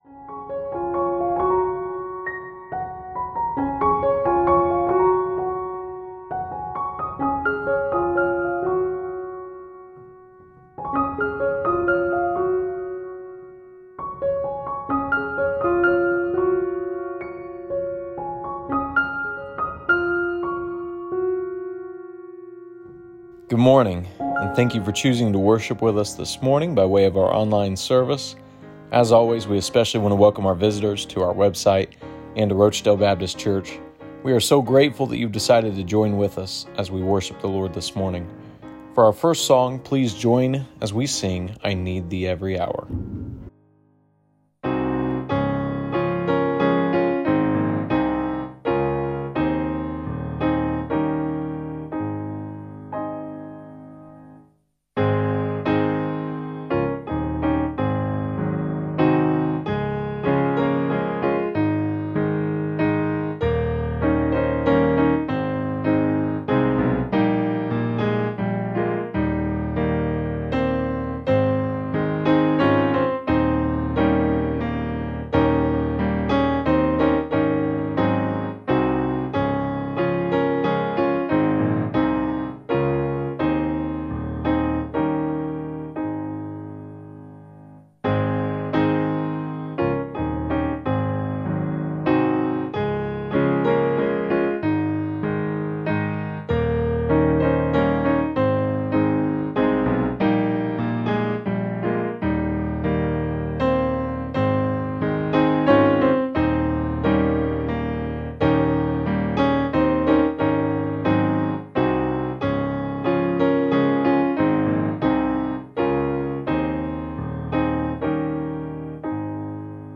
Sermons Devotions Check out our most recent posts!